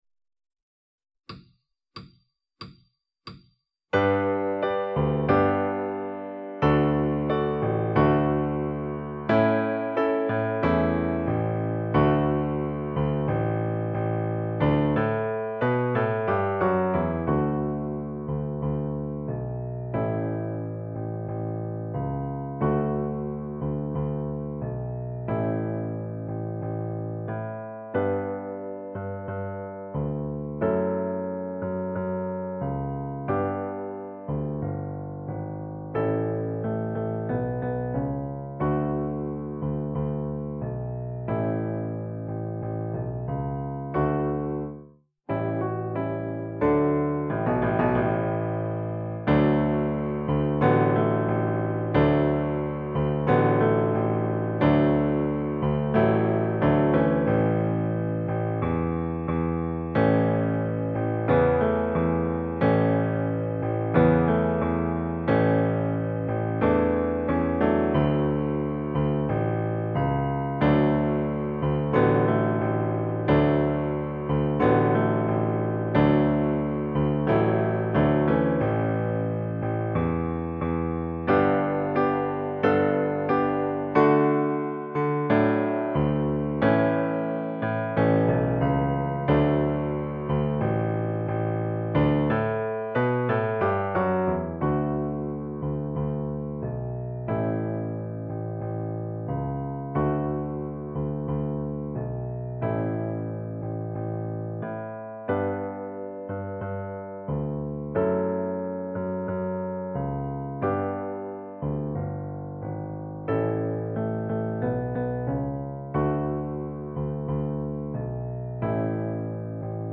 base lenta